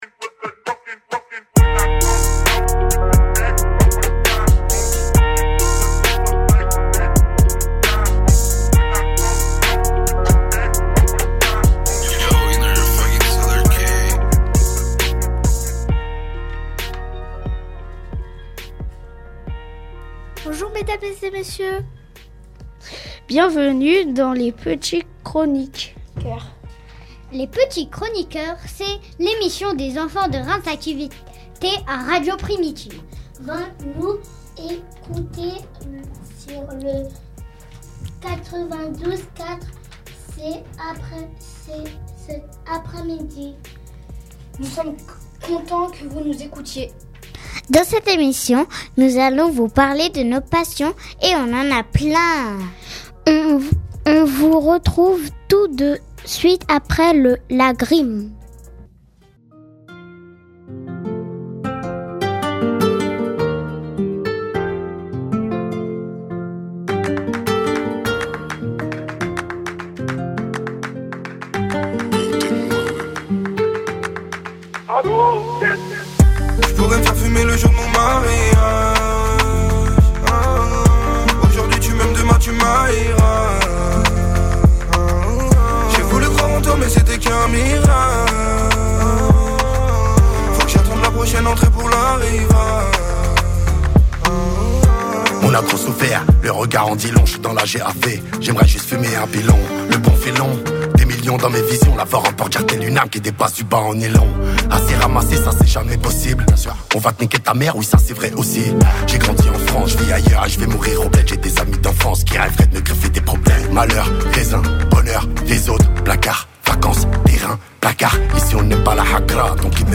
Une nouvelle émission réalisée entièrement par les jeunes de Reims Activ' Eté